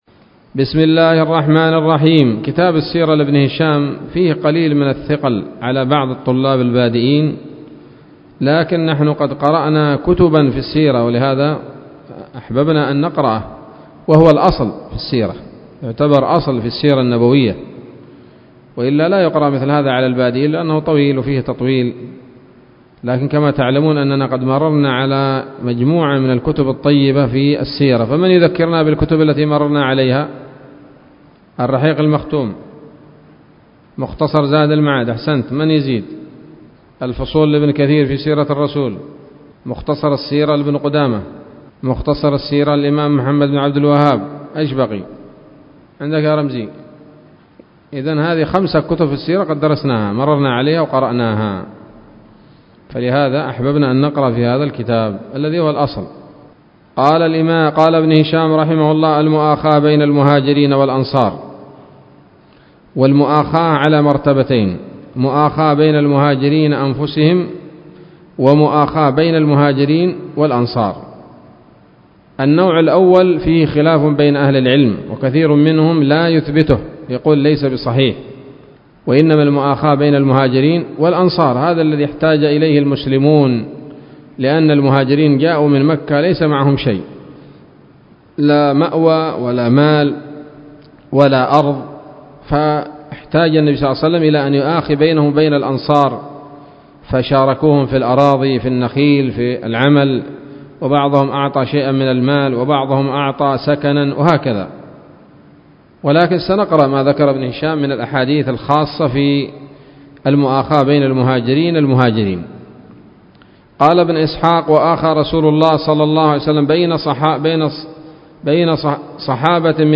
الدرس التاسع والسبعون من التعليق على كتاب السيرة النبوية لابن هشام